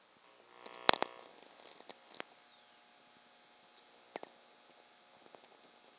Archived Whistler Event Data for 2024-05-12 Forest, VA USA